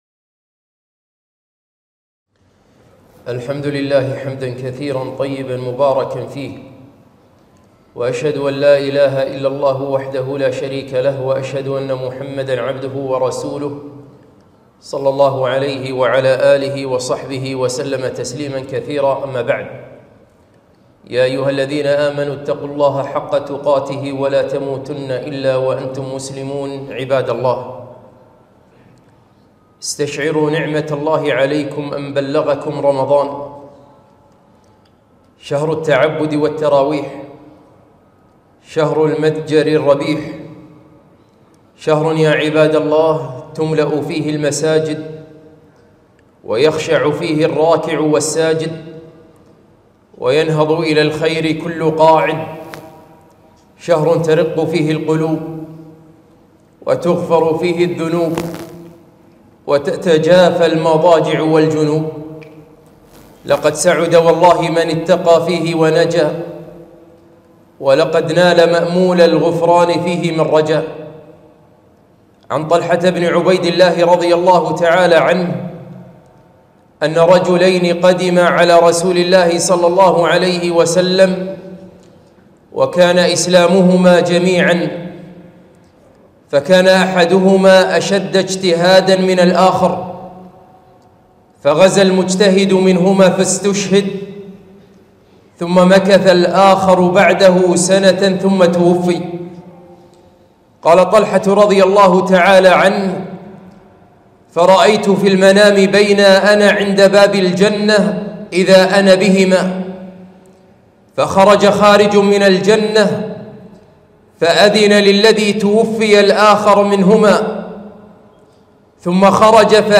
خطبة - الأيام الأخيرة من رمضان